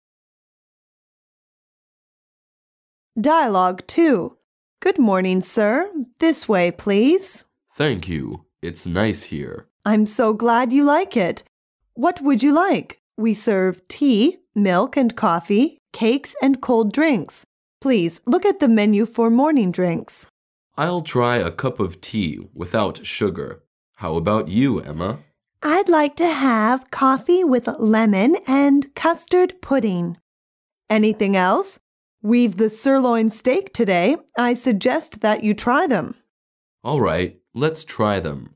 Dialouge 2